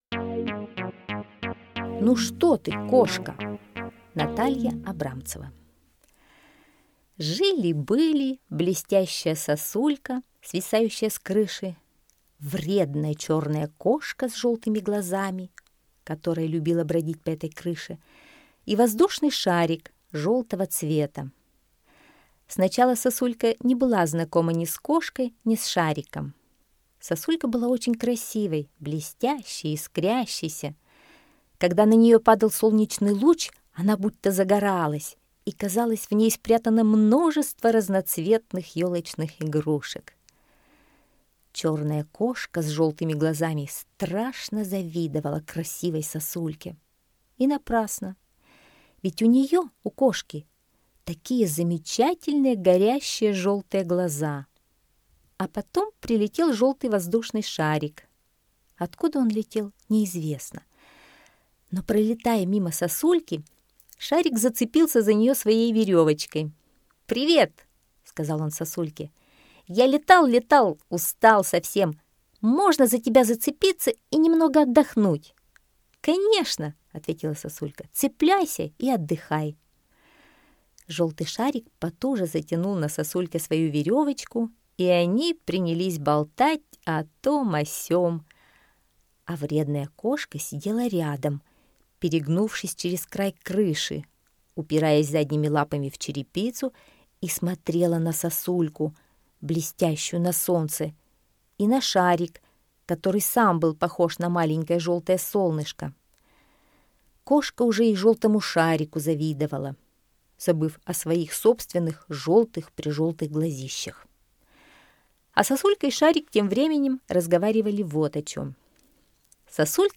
Аудиосказка «Ну что ты, кошка!»